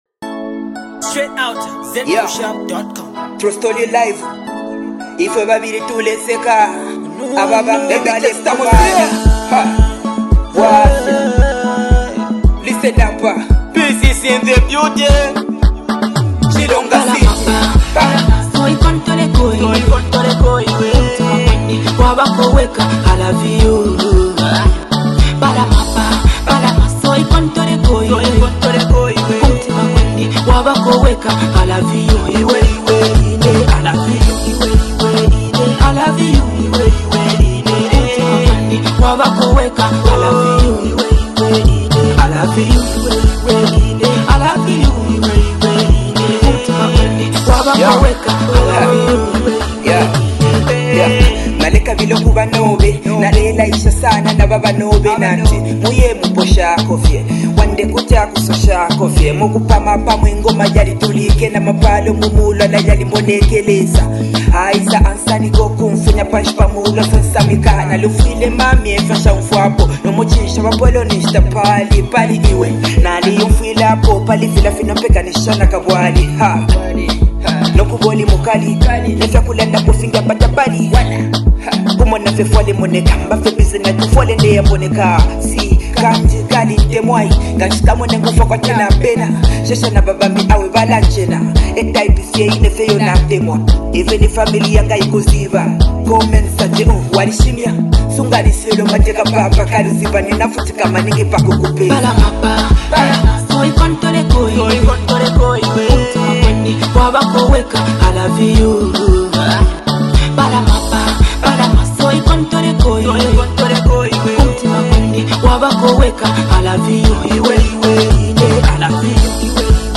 emotional love joint